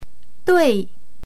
そうです。 ドゥイ
発音は厳密には「ドゥェイ」というように「ェ」の音を軽く入れます。